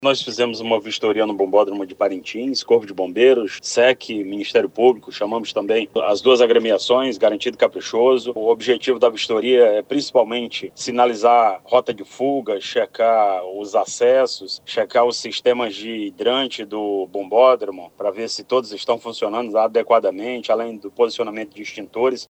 O comandante-geral dos Bombeiros, coronel Orleilson Muniz, destacou que a vistoria é fundamental para garantir segurança ao grande público esperado.
SONORA-1-Orleison-Muniz.mp3